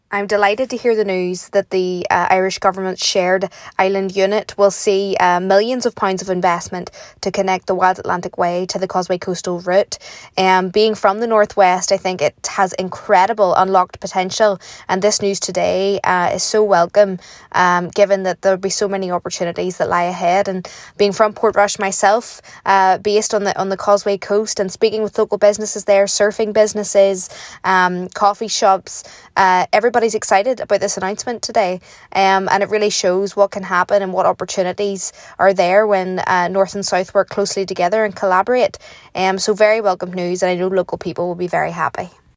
Derry MLA Cara Hunter has welcomed the news, saying it will be a massive boost for the North West’s tourism offering: